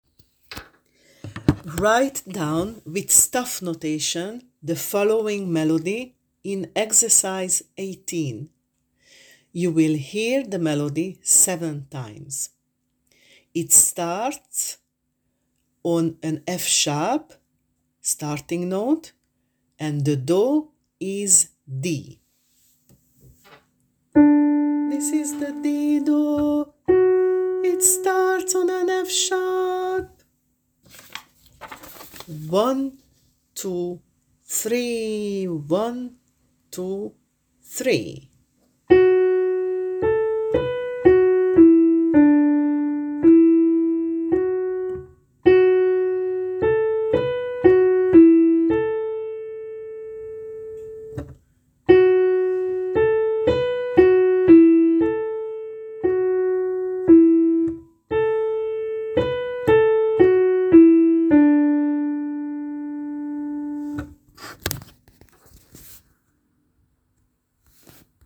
You will hear it 7 times: